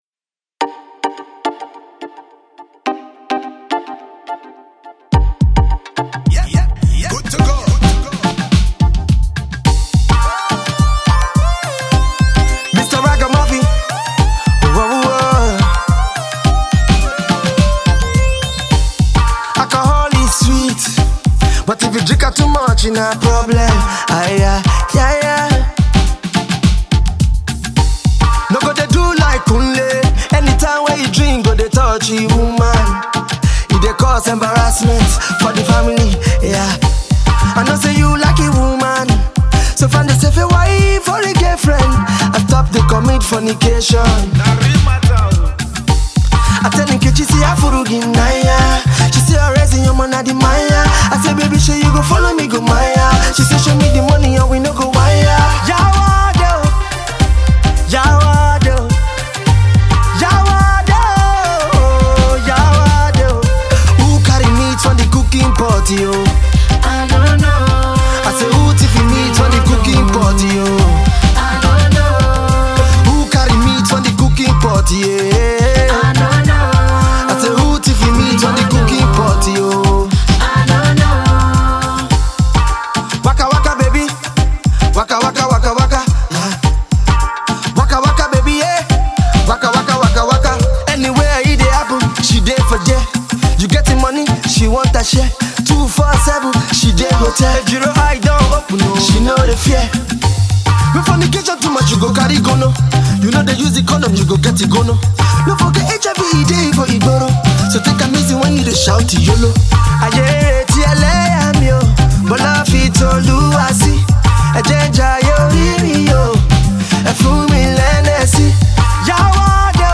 afrobeat tune